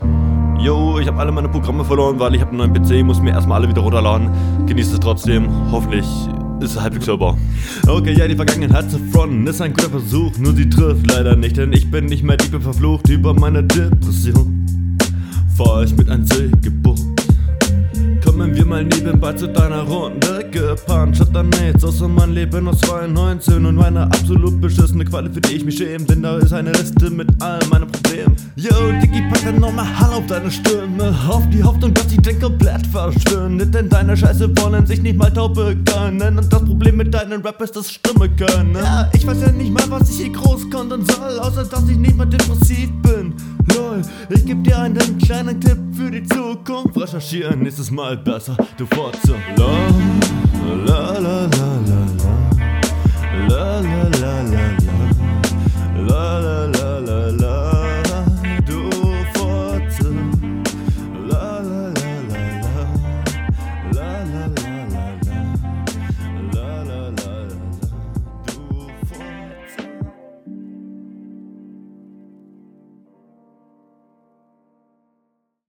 Dann downloade die doch kurz lol Geht vom grundsätzlichem Flow klar.